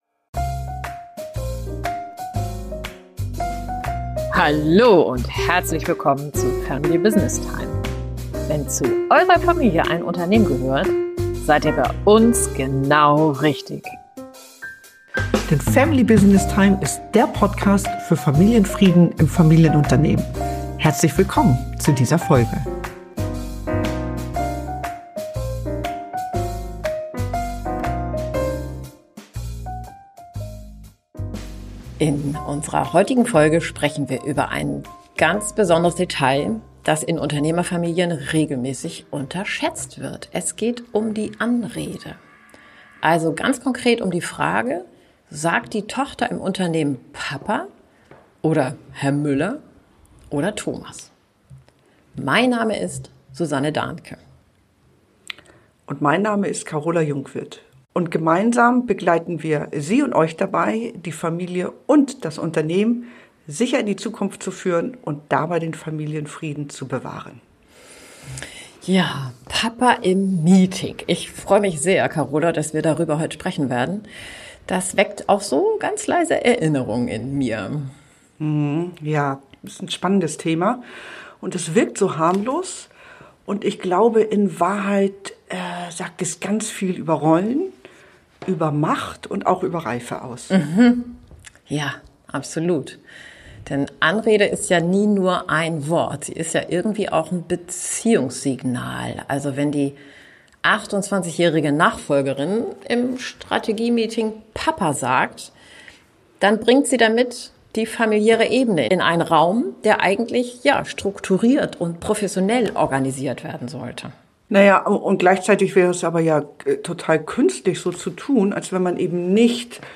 Sie ist ein Beziehungssignal, das darüber entscheidet, welche Ebene in einen Raum kommt – die familiäre oder die professionelle. Ein spannendes Gespräch über Bewusstsein, Grenzen und die Kunst, zwischen Nähe und Professionalität wechseln zu können.